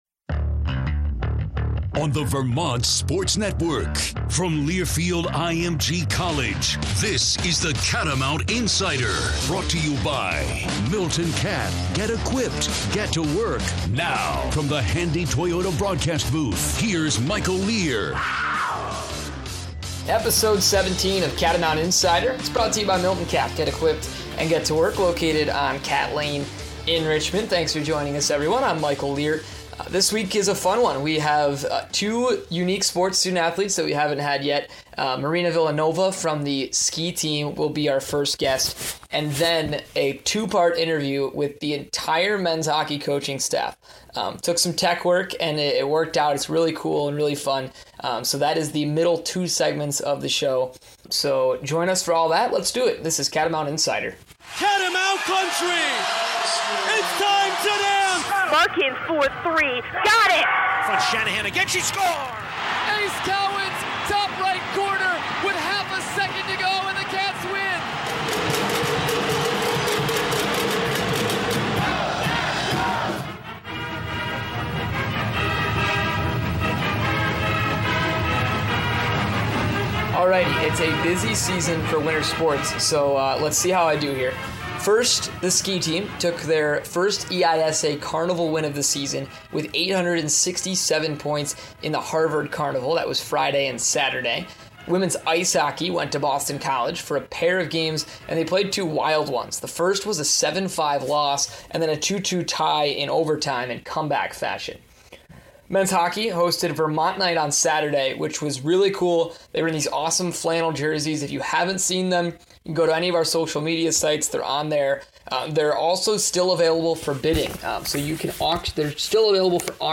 Episode 17 of Catamount Insider features great interviews with….